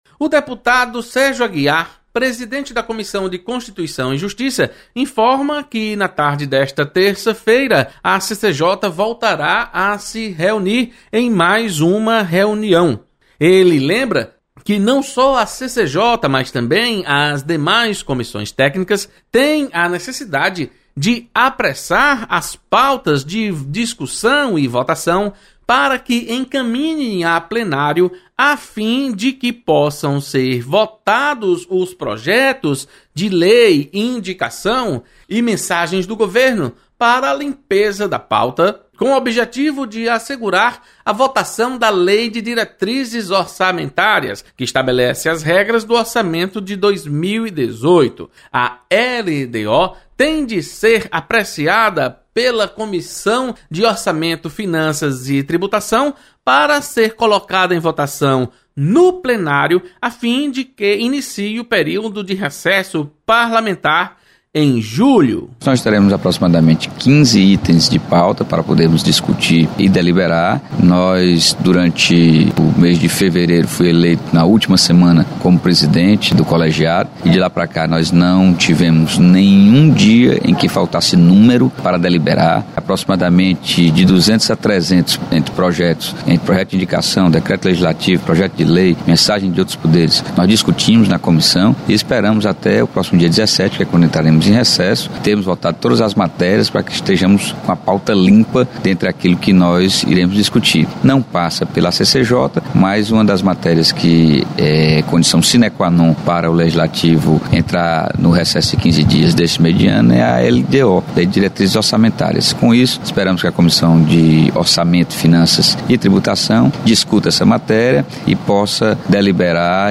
CCJ realiza reunião nesta terça-feira. Repórter